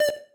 synth3_6.ogg